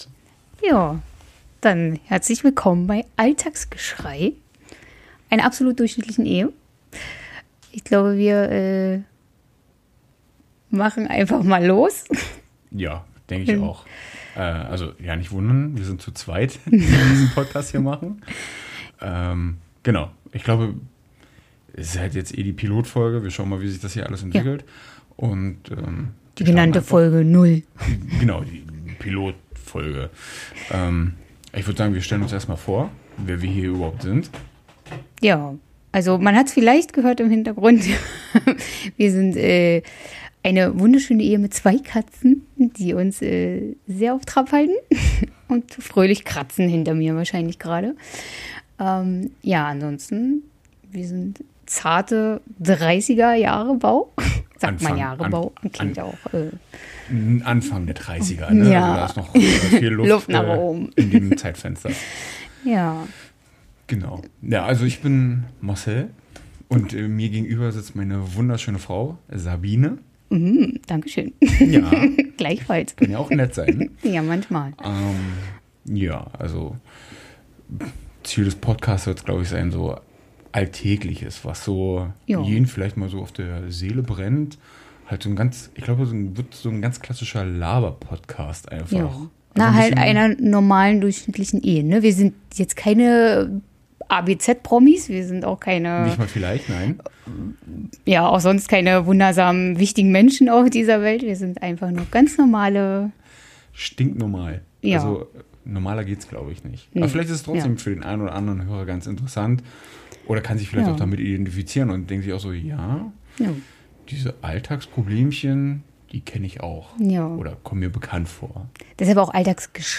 Ein stink normales Ehepaar versucht sich an seinem ersten Podcast.